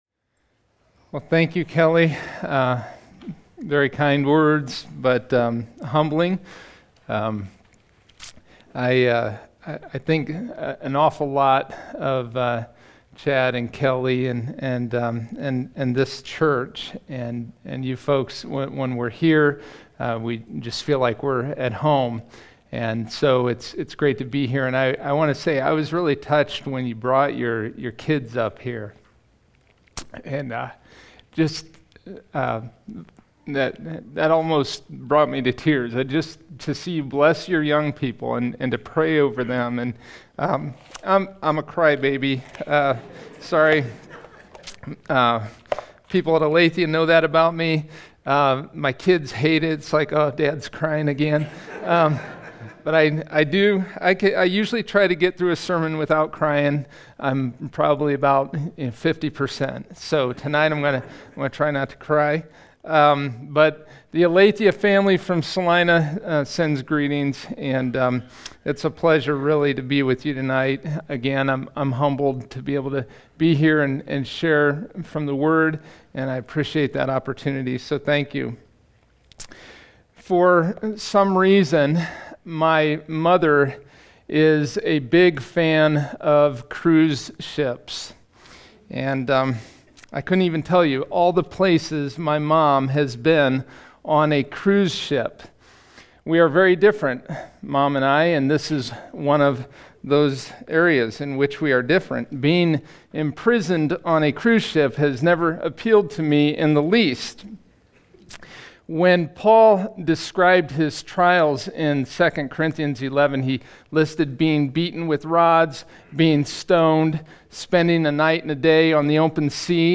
Sermon 08/03: David and Jonathan